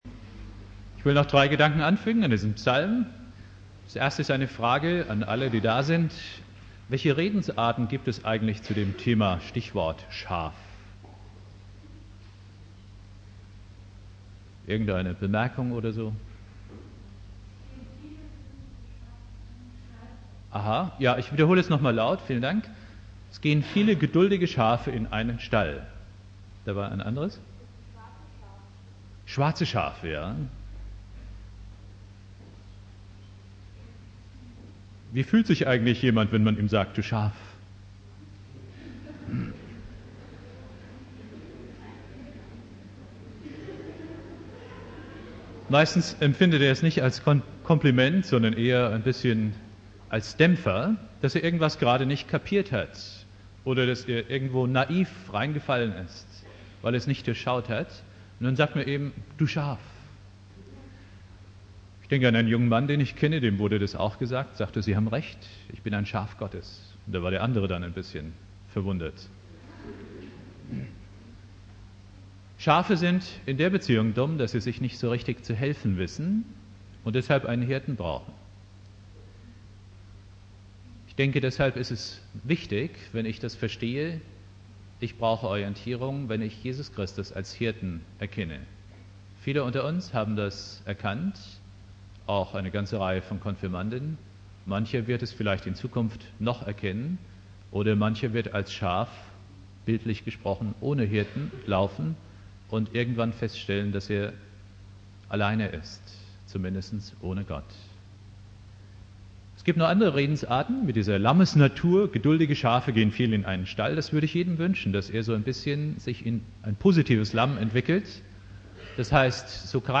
Kurzpredigt zu Psalm 23 im Vorstellungsgottesdienst der Konfirmanden aus Obertshausen Bibeltext